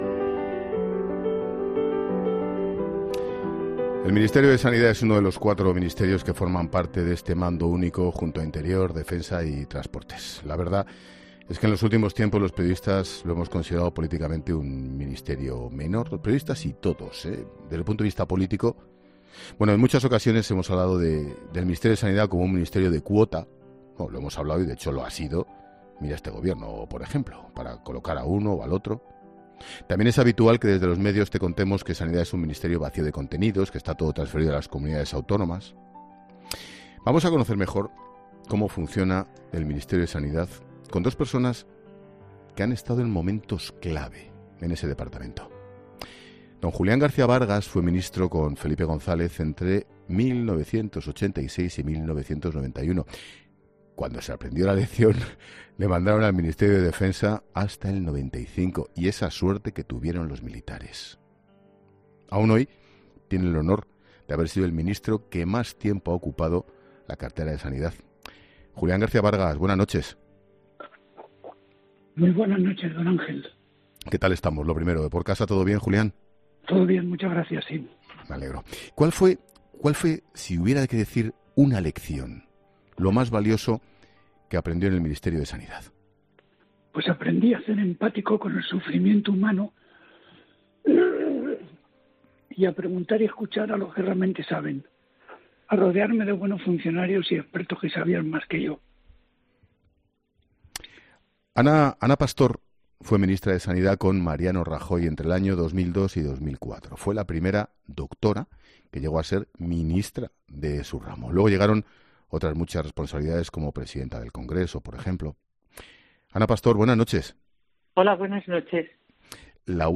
ESUCHA EL EDITORIAL DE CARLOS HERRERA